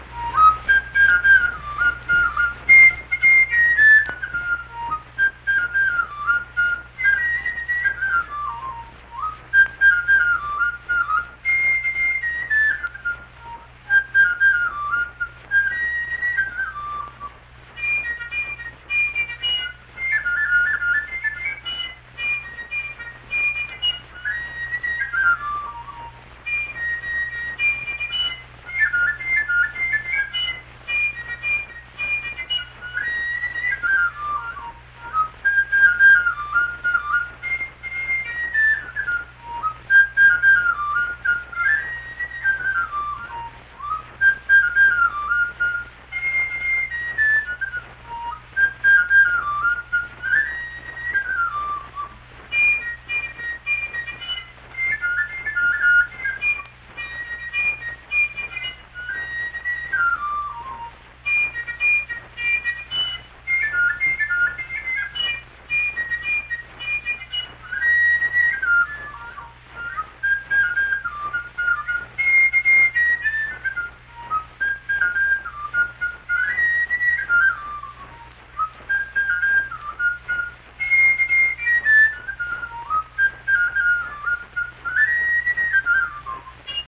Unaccompanied fife music
Performed in Hundred, Wetzel County, WV.
Instrumental Music
Fife